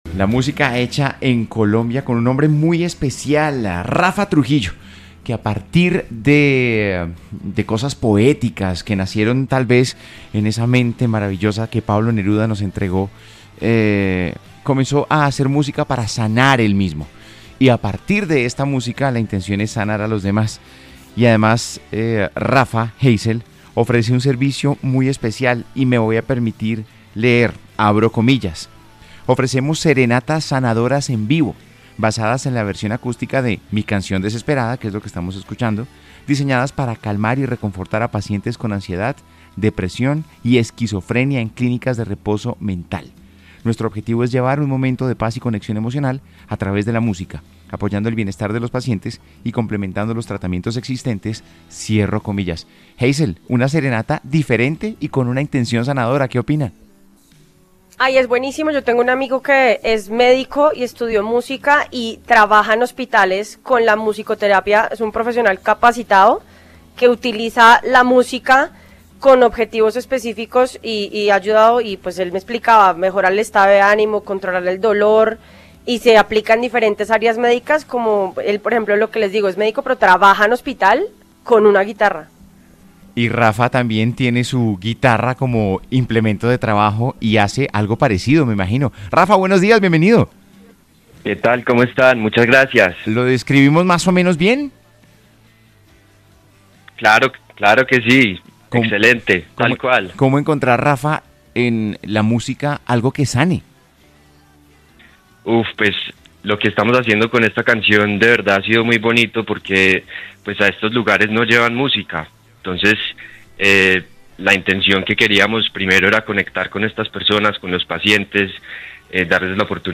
una obra musical cargada de melancolía y desasosiego